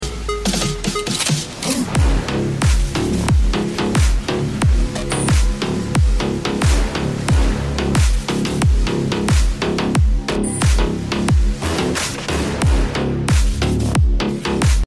Fpv Drones Attack military Vehicle sound effects free download